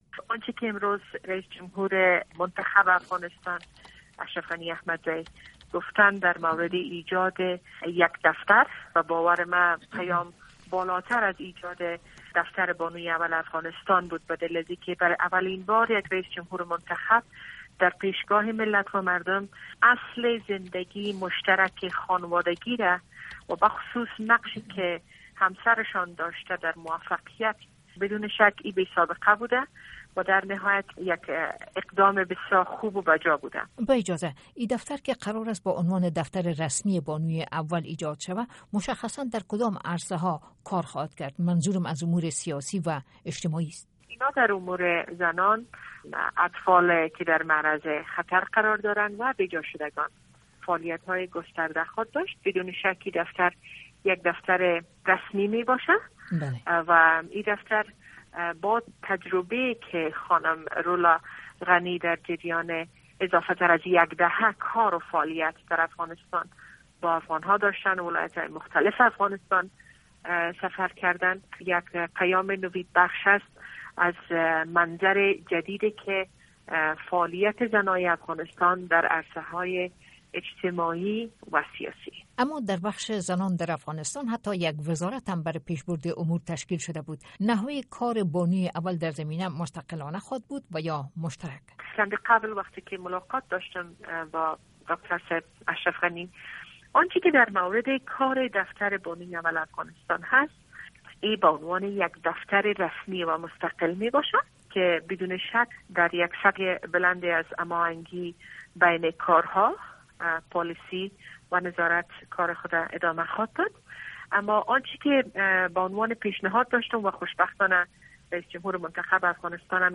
shukraia barakzai interview